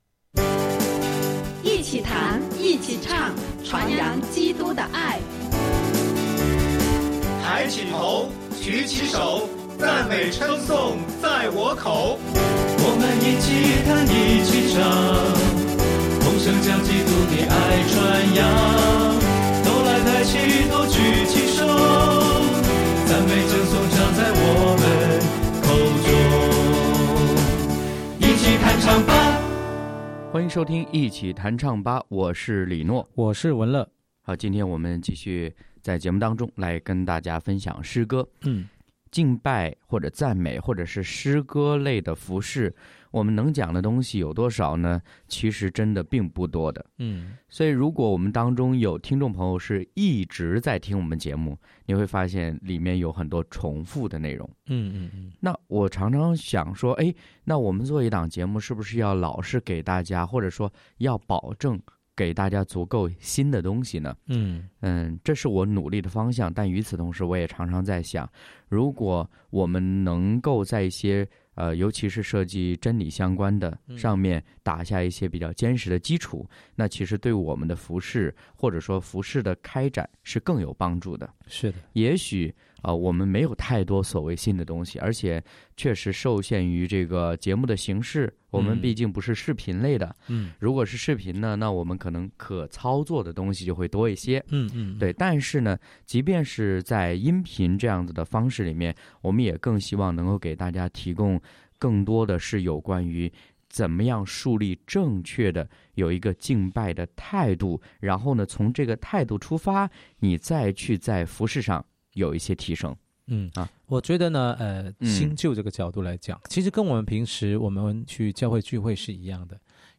一起弹唱吧！
敬拜分享：被召，原是来敬拜；诗歌：《万国都要来赞美你》、《开路者》